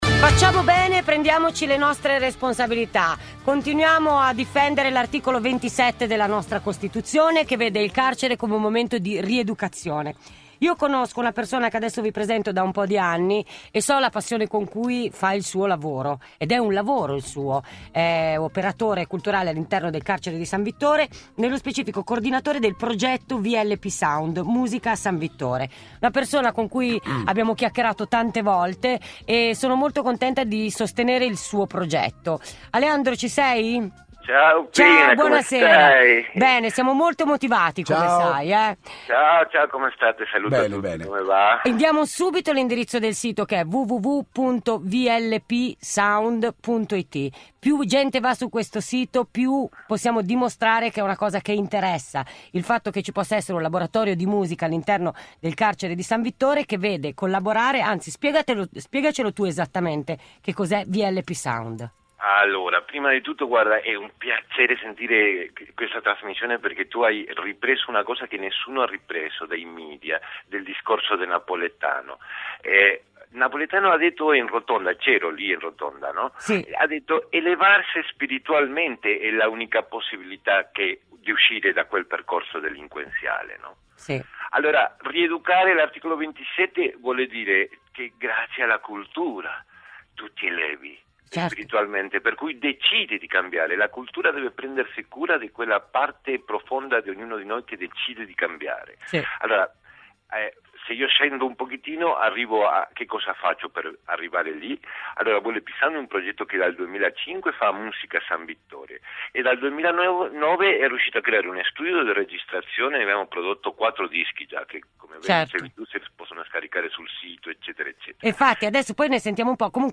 Intervista a “Radio Deejay”